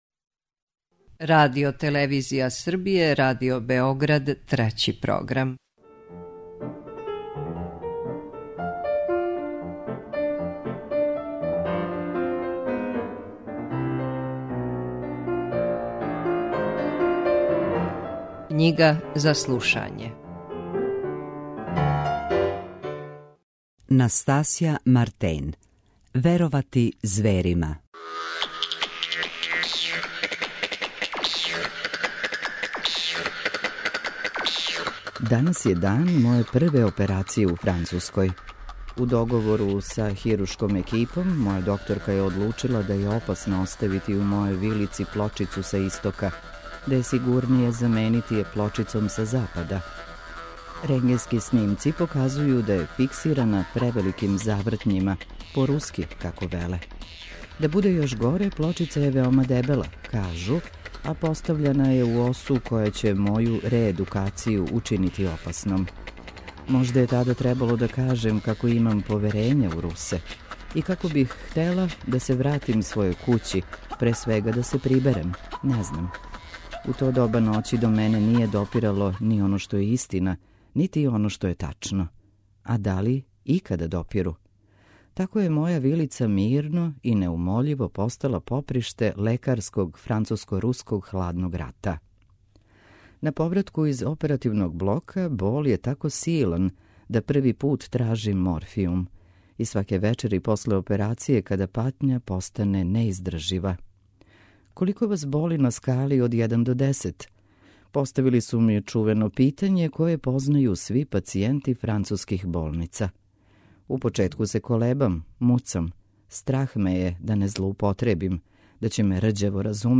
Спикерска интерпретација текста